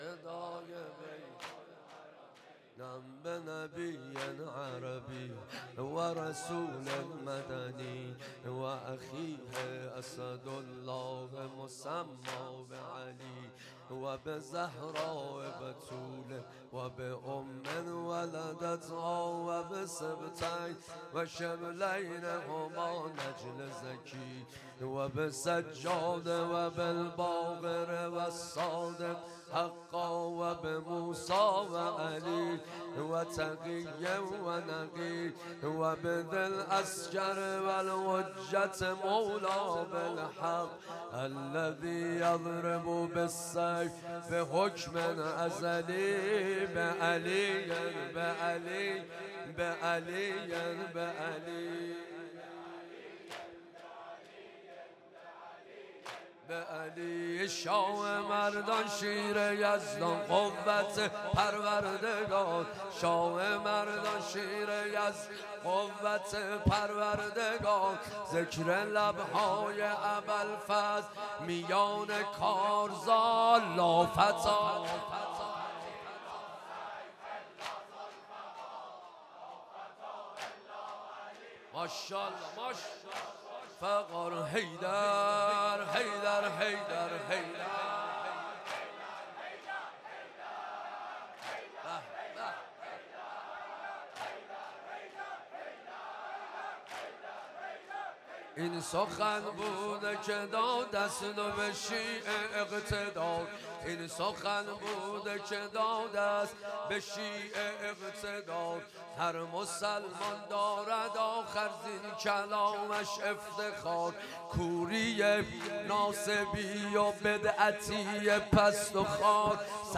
سنگین/عربی